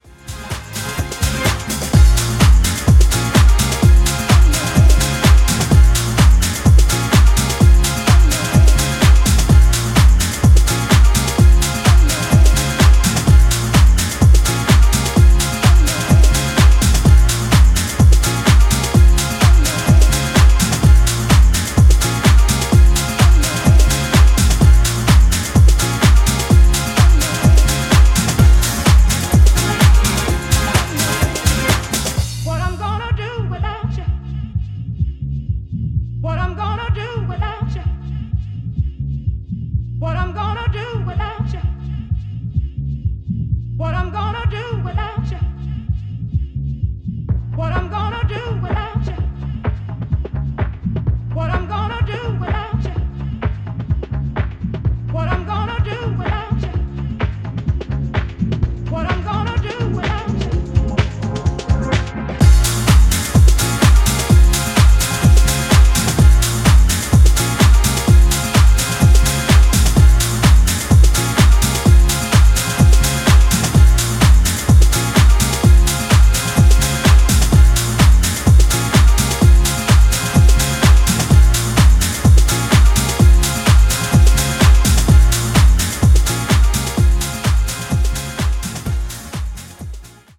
全4曲リズミカルで爽やかなディスコ＆ディープなハウスサウンドに仕上がった大スイセンの1枚です！！
ジャンル(スタイル) DISCO HOUSE